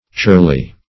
Churly \Churl"y\, a.
churly.mp3